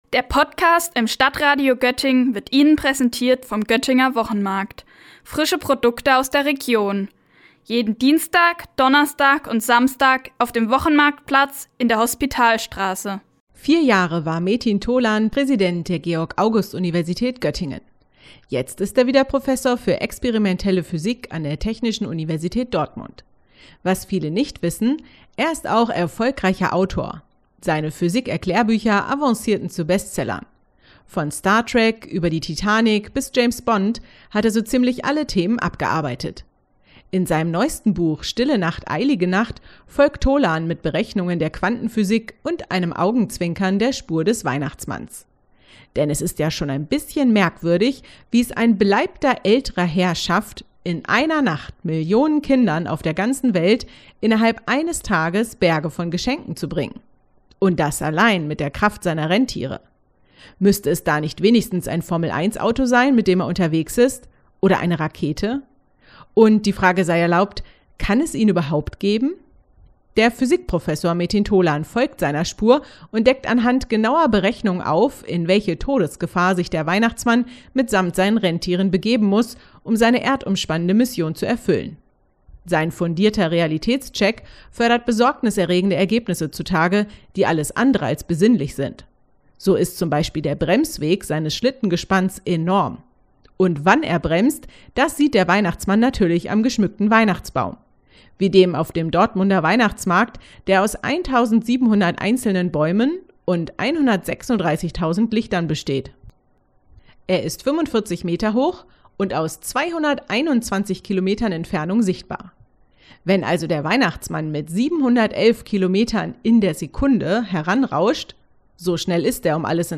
Beiträge > Buchrezension: „Stille Nacht, eilige Nacht” von Metin Tolan - StadtRadio Göttingen